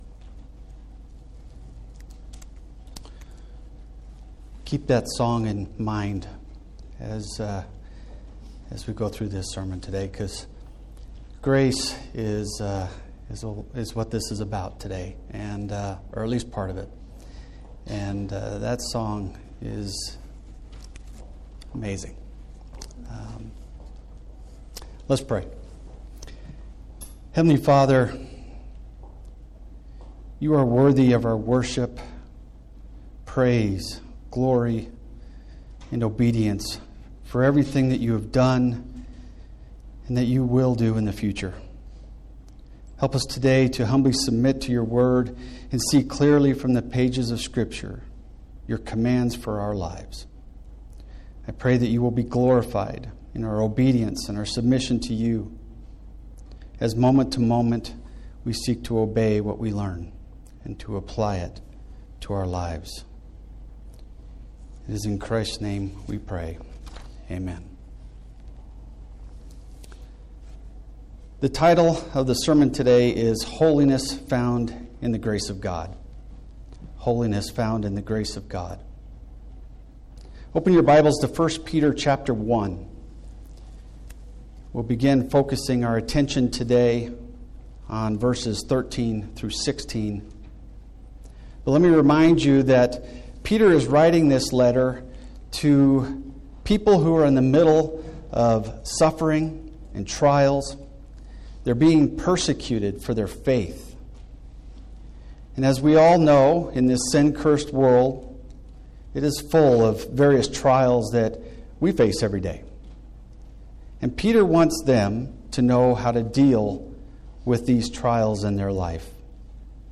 preaches through 1 Peter chapter 2